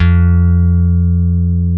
F2 2 F.BASS.wav